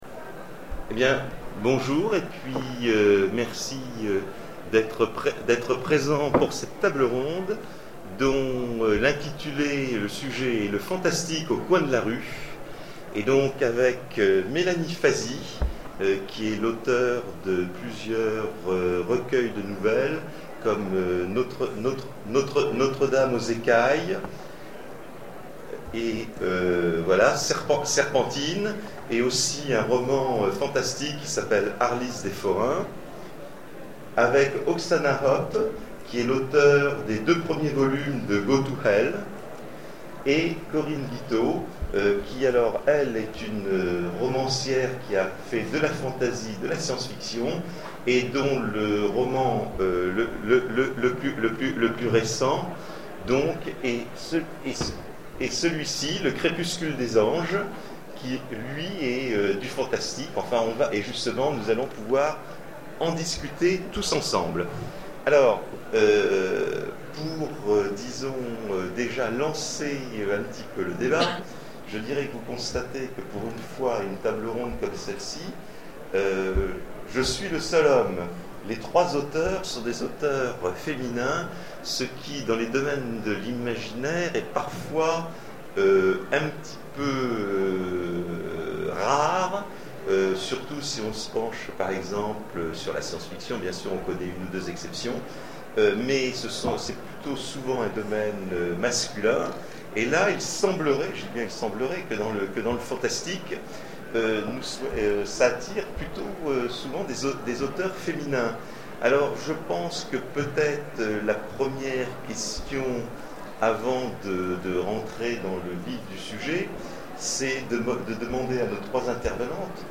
Zone Franche 2013 : Conférence Le fantastique au coin de la rue